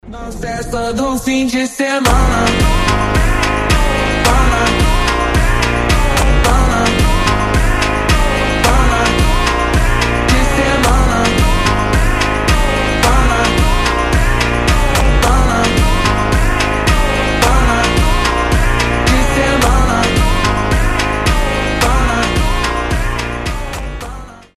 Танцевальные рингтоны , Рингтоны техно , Фонк